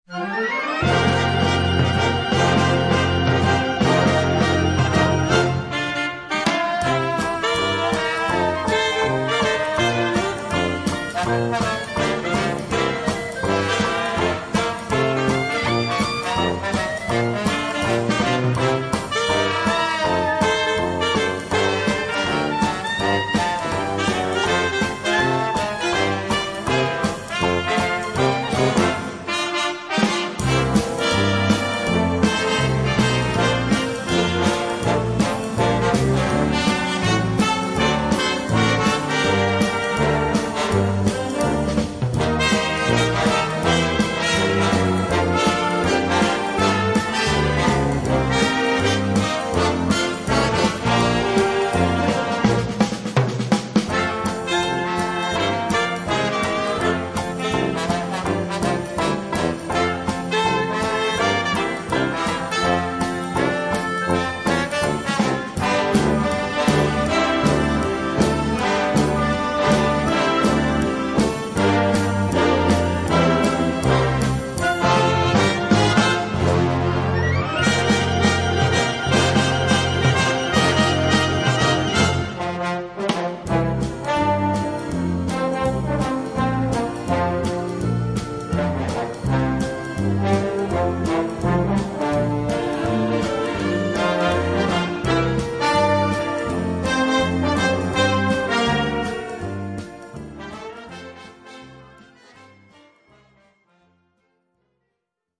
Gattung: Dixie-Ensemble und Blasorchester
Besetzung: Blasorchester
Für Blasorchester, Dixielandband und Schlagzeug.